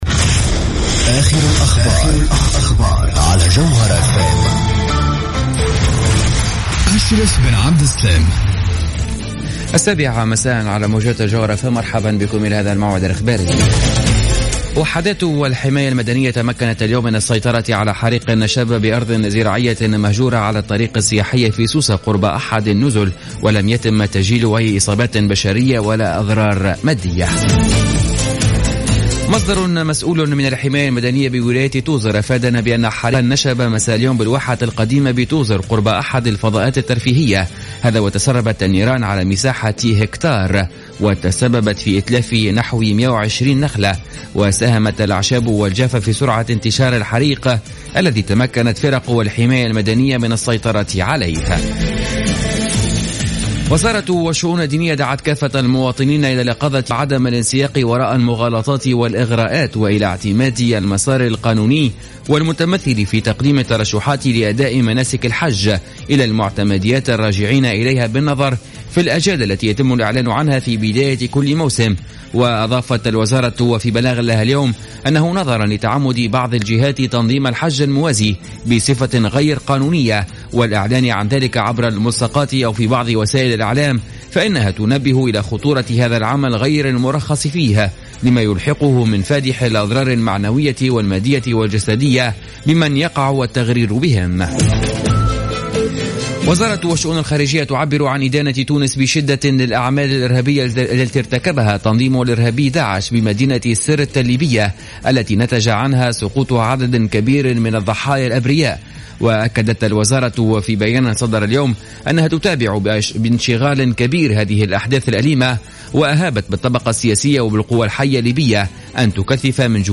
نشرة أخبار السابعة مساء ليوم السبت 15 أوت 2015